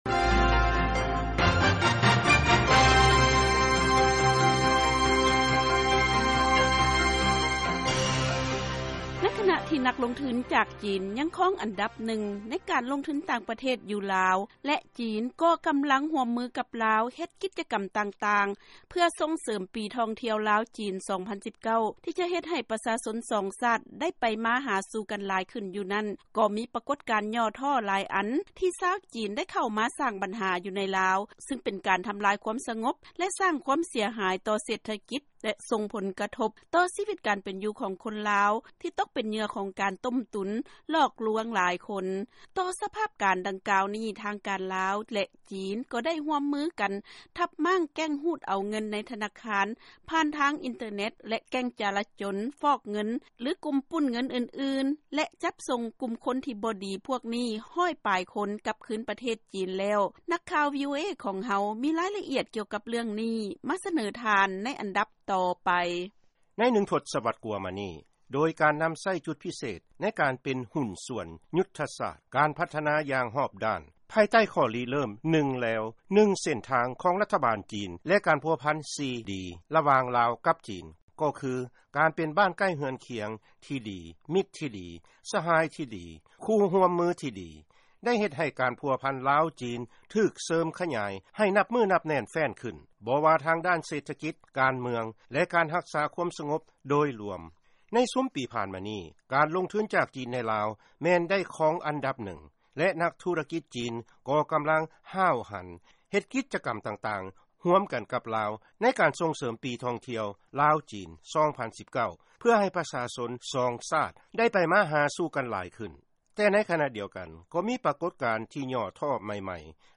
ເຊີນຟັງລາຍງານກ່ຽວກັບການທັບມ້າງແກັ່ງຫລອກລວງ, ປຸ້ນຈີ້ ແລະຟອກເງິນ ຊາວຈີນໃນລາວ